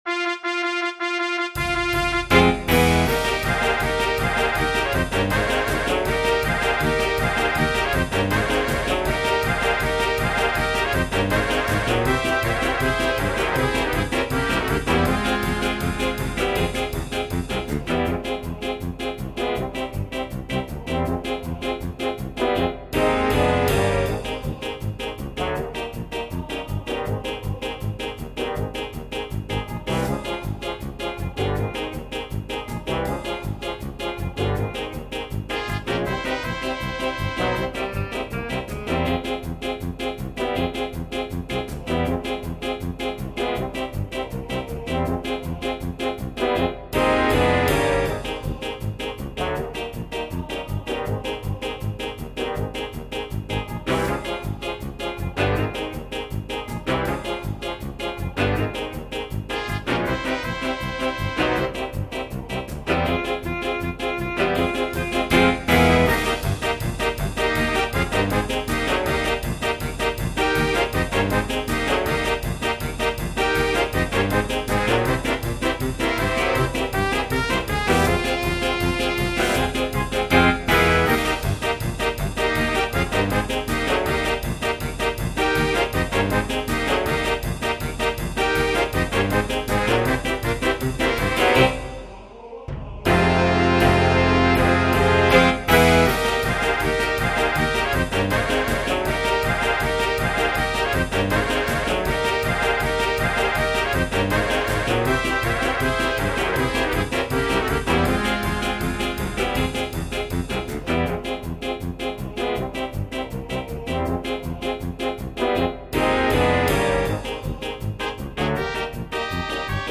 a-moll
koncert band